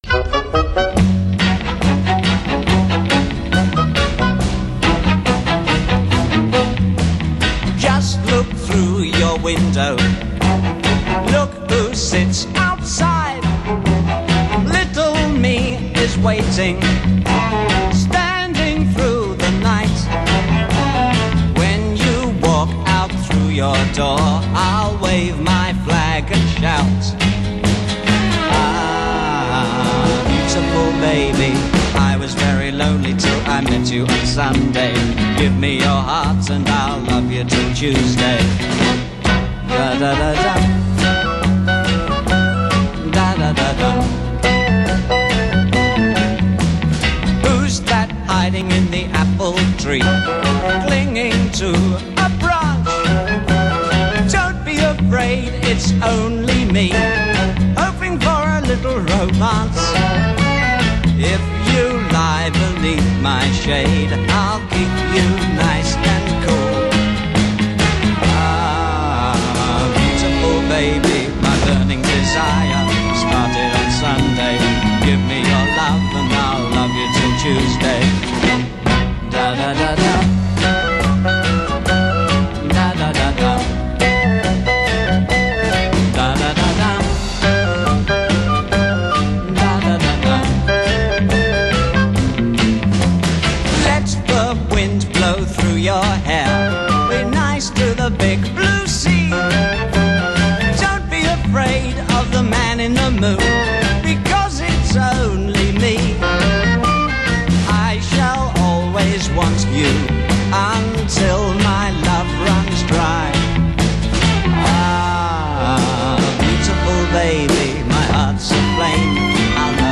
excerpts of two sessions, poles apart in style.